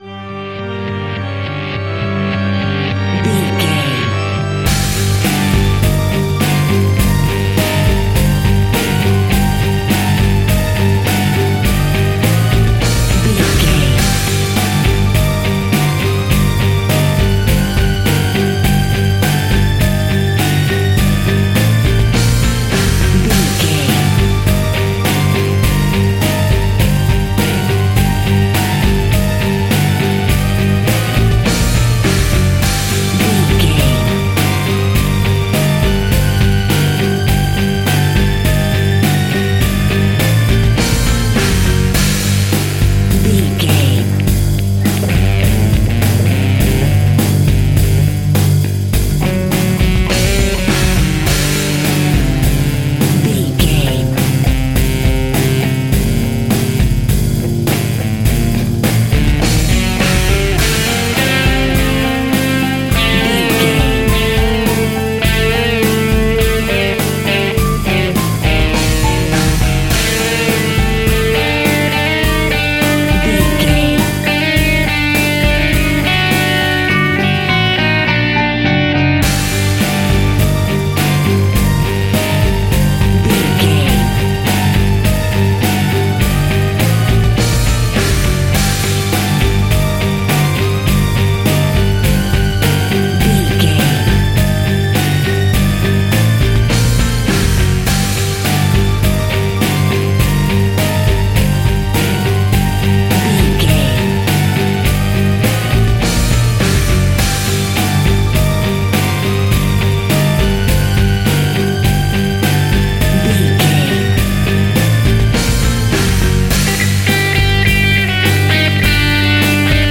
In-crescendo
Aeolian/Minor
ominous
dark
suspense
haunting
eerie
strings
synth
ambience
pads